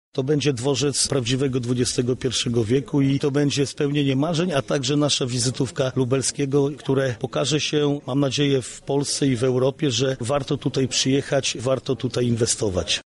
Również marszałek województwa, Jarosław Stawiarski zauważa wielką rolę obiektu, który ma powstać niedaleko obecnego dworca kolejowego: